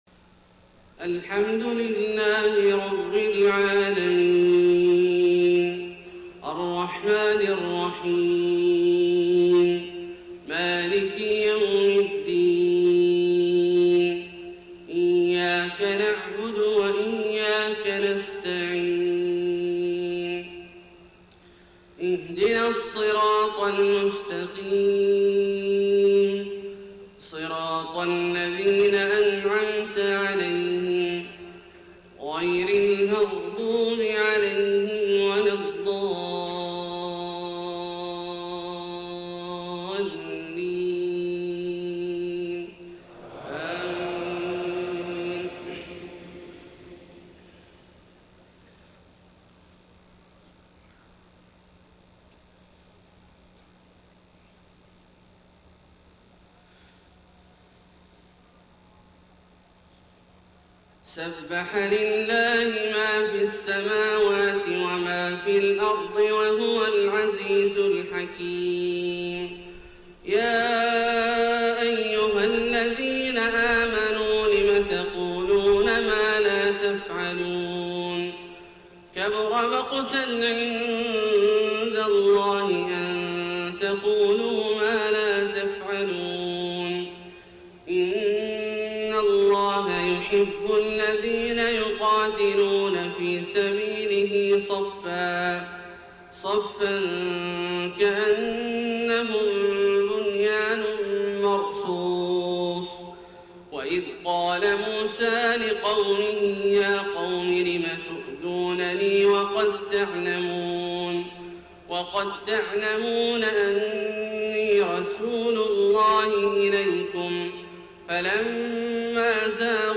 صلاة الفجر 13 ربيع الأول 1431هـ سورة الصف > 1431 🕋 > الفروض - تلاوات الحرمين